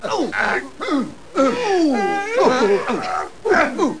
1 channel
00357_Sound_fight.mp3